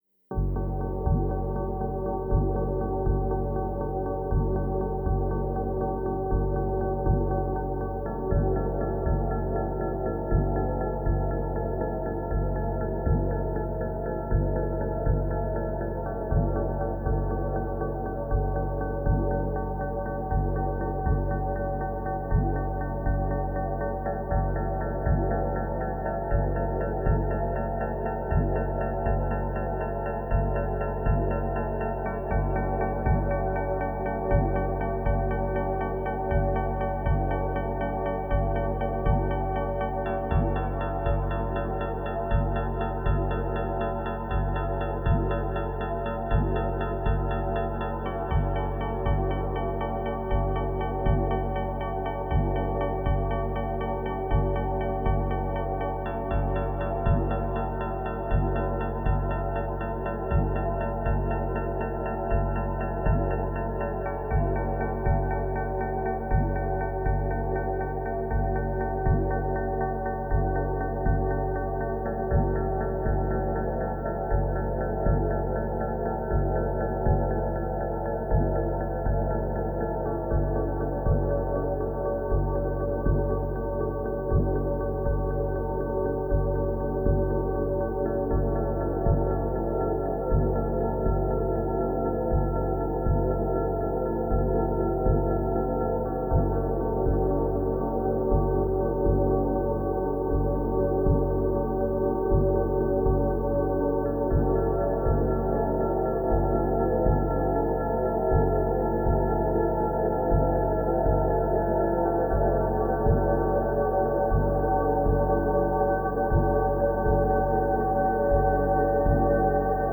modular_ambient_jam.mp3